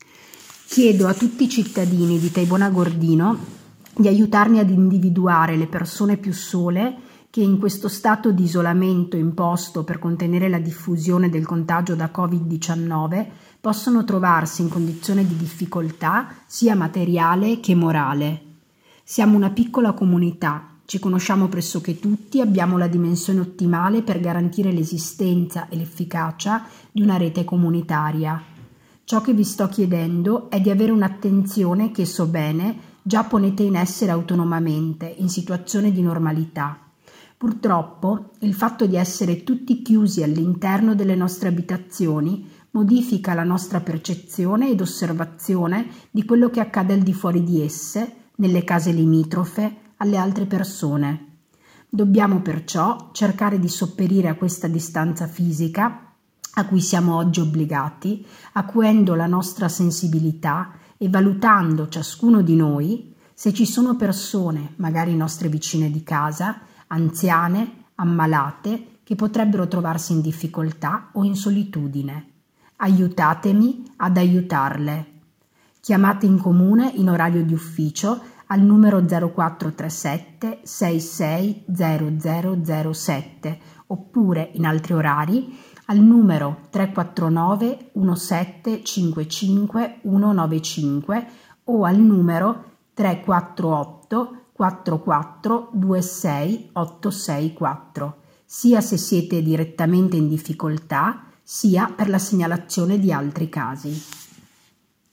SILVIA TORMEN, SINDACO DI TAIBON AGORDINO